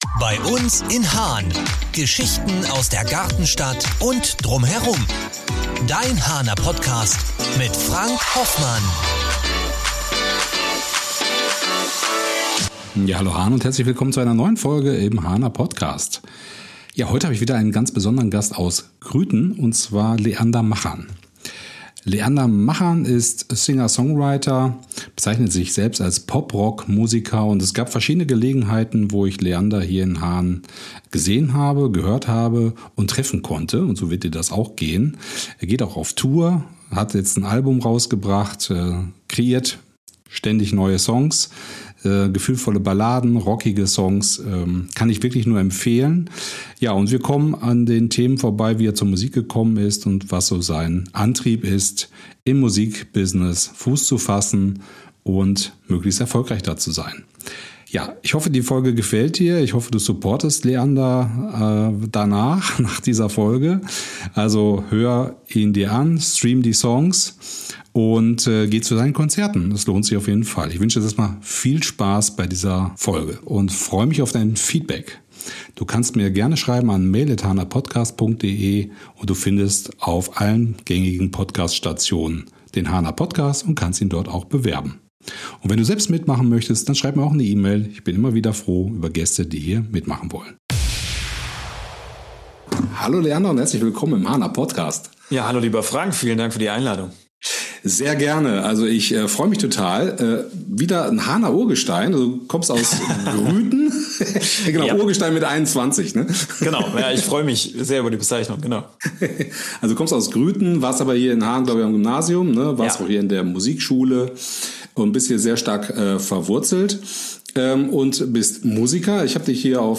Wir reden über Musik, Mut und das echte Leben auf und neben der Bühne. Ein Gespräch voller Energie, Haaner Herzblut und ehrlicher Worte.